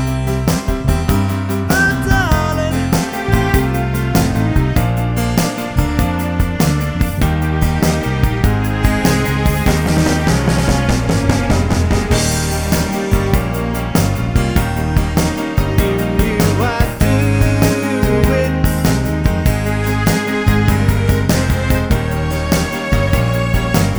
no Backing Vocals Rock 'n' Roll 4:01 Buy £1.50